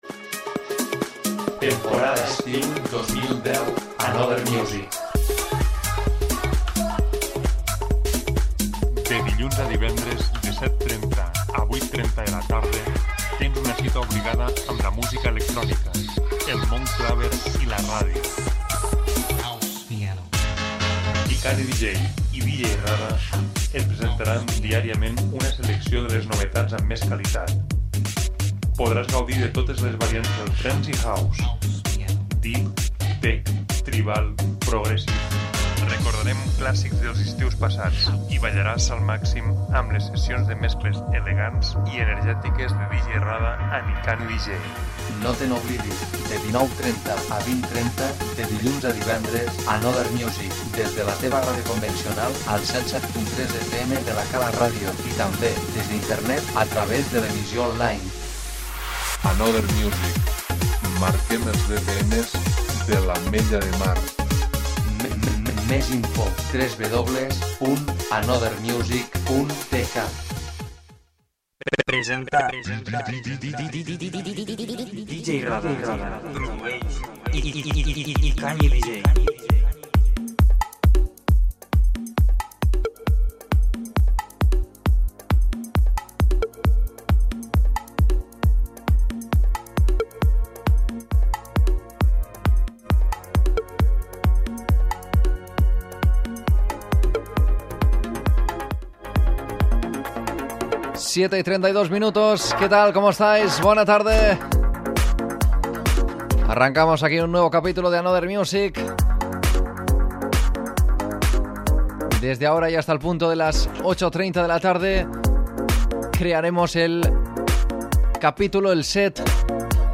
tech/progressive/vocal-house